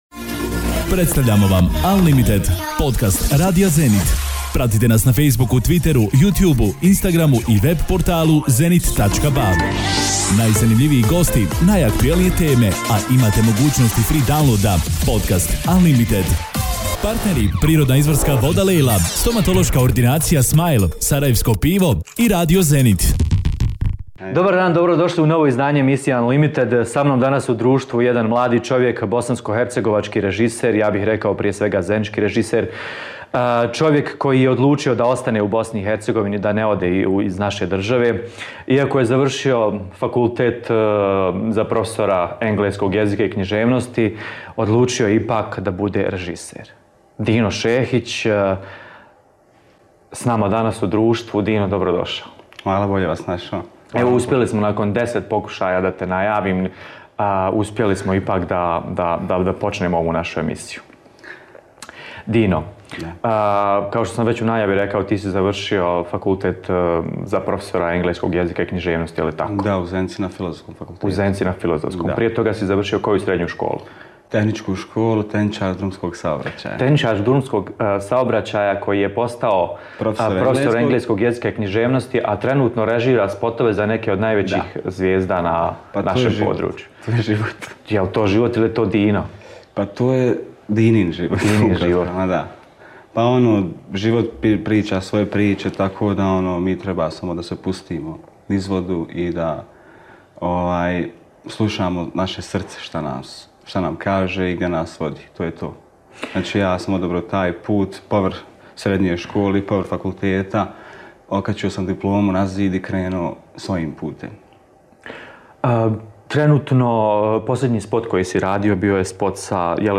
Početak je toliko opušten da smo najavu morali snimati desetak puta.